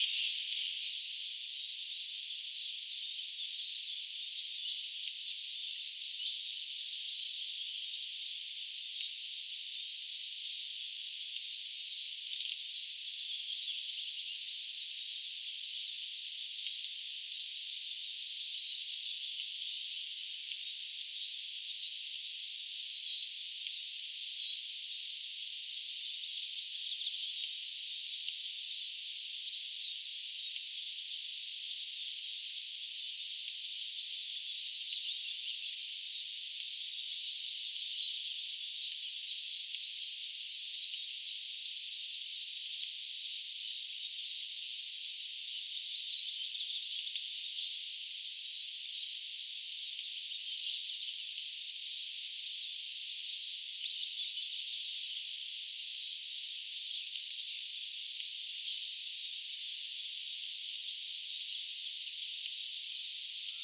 sinitiaispesue
sinitiaispesue.mp3